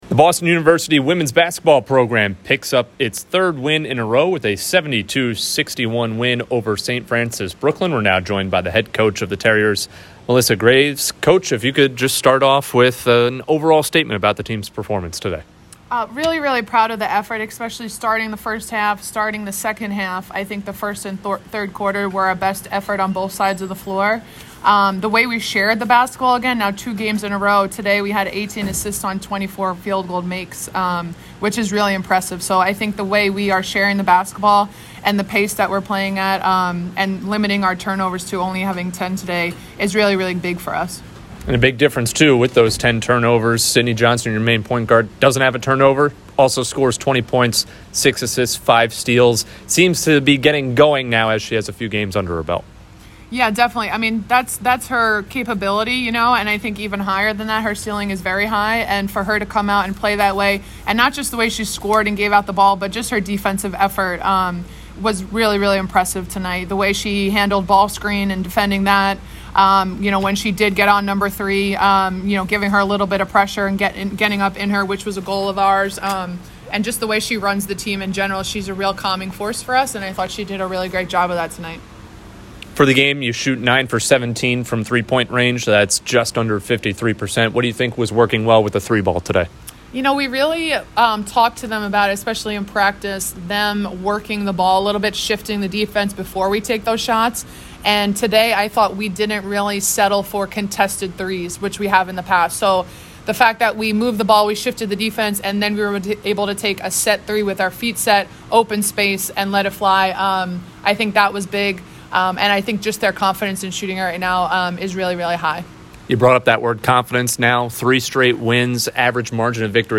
WBB_SFBK_Postgame.mp3